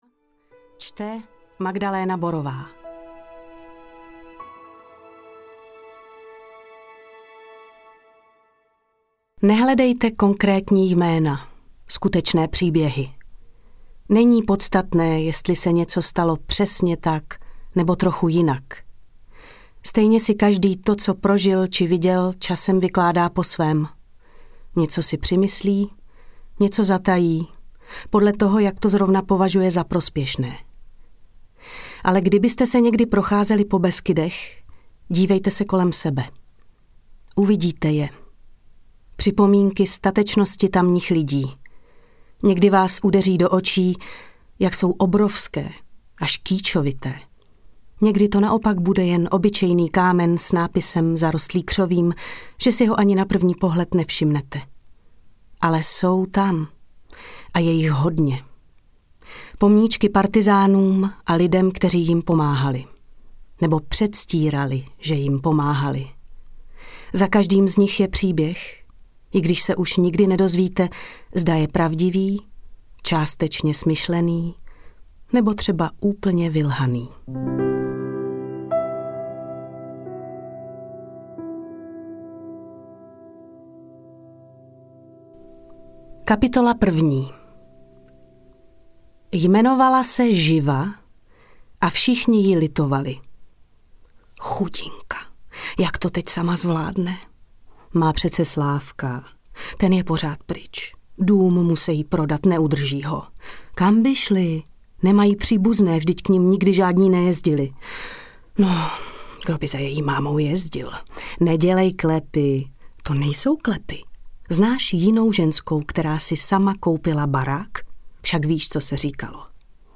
Když přišli psi audiokniha
Ukázka z knihy